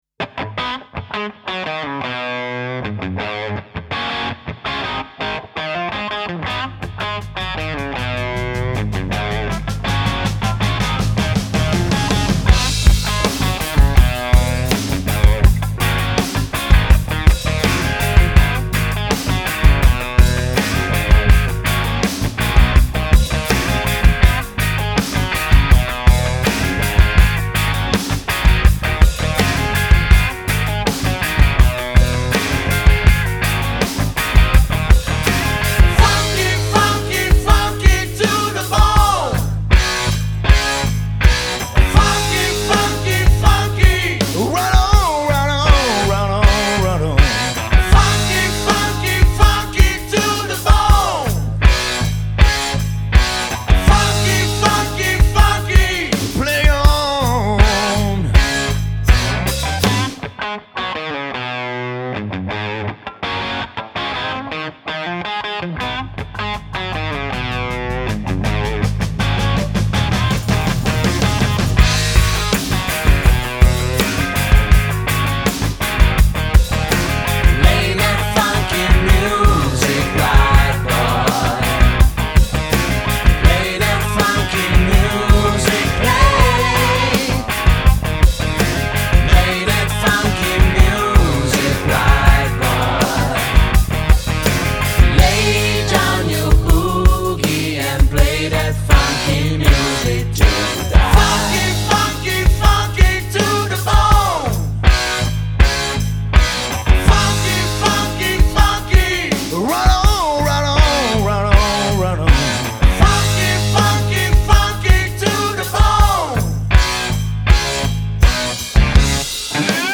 Das ist sowas von Vinylartig.
das ist für meine ohren southern rock...